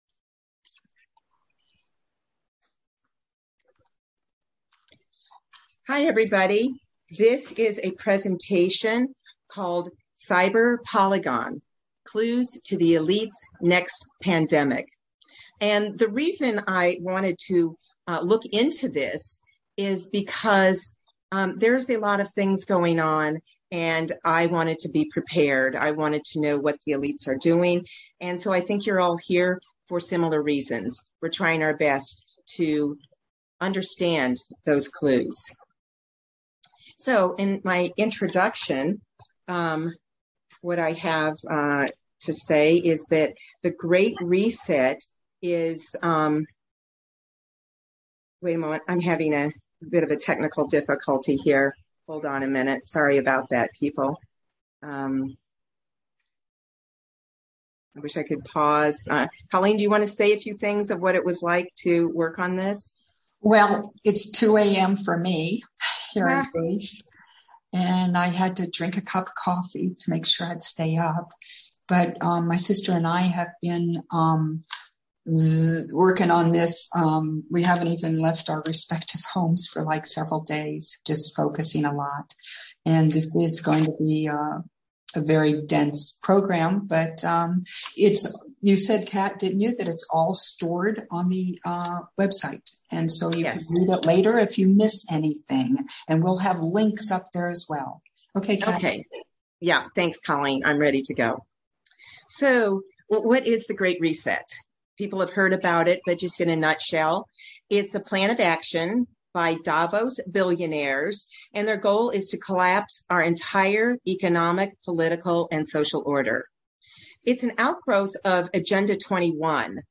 Zoom presentation